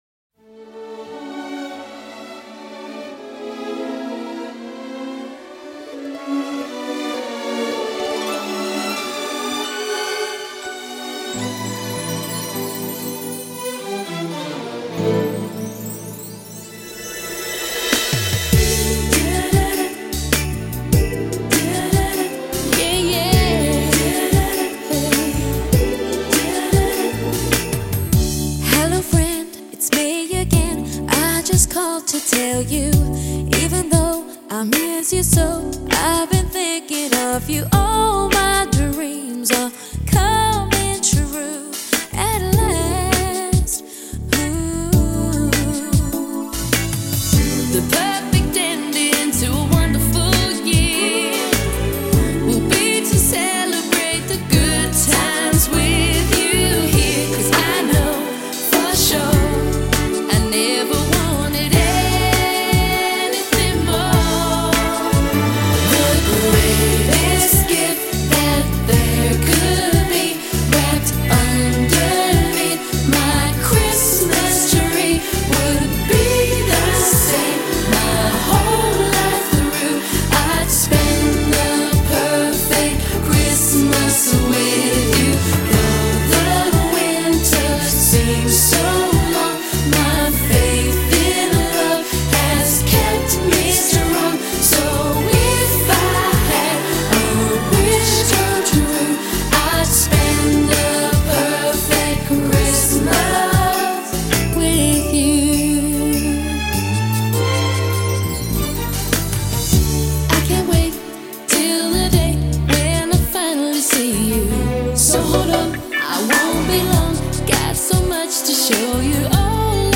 manufactured pop act